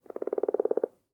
assets / minecraft / sounds / mob / frog / idle7.ogg